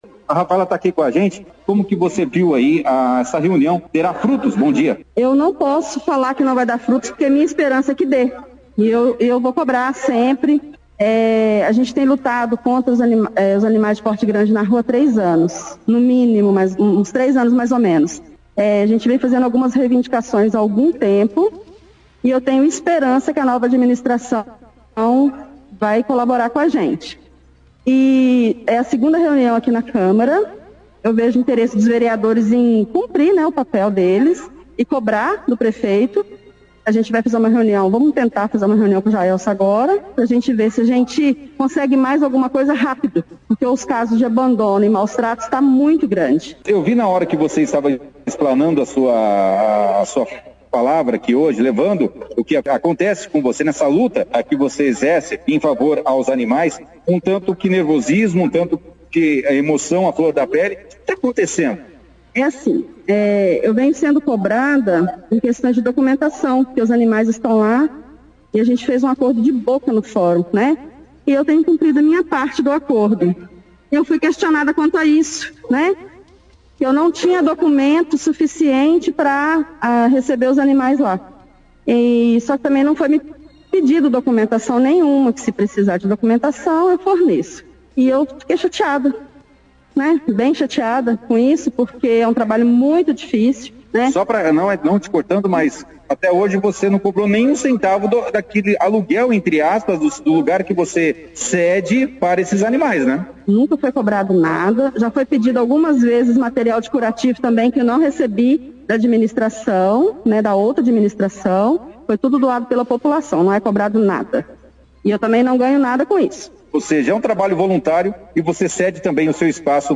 A reunião foi destaque dentro do jornal Operação Cidade